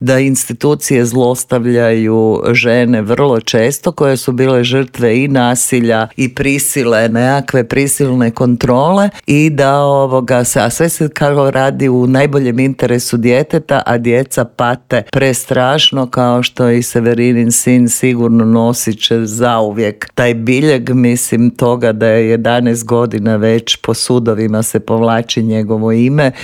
Gostujući u Intervjuu Media servisa